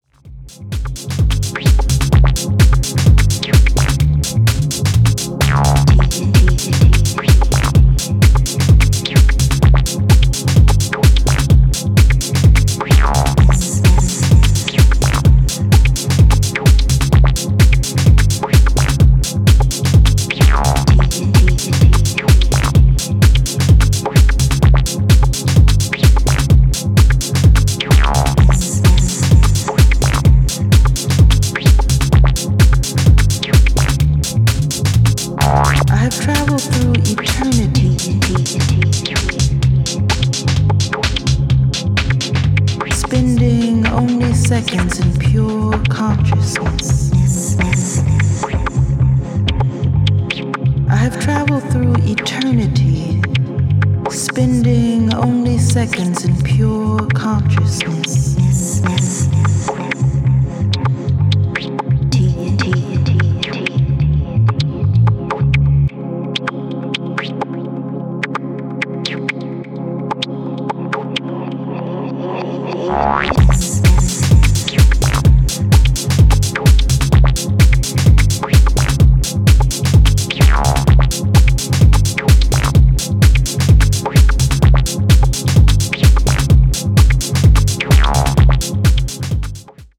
ダウナーなパッドやトリッピーなトビ音、女性ヴォーカルのサンプルが絶妙なトーンをキープする